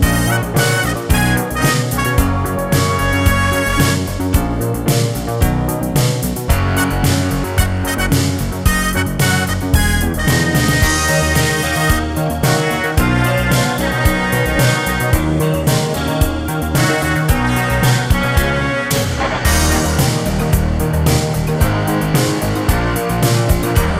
Two Semitones Down Soundtracks 4:07 Buy £1.50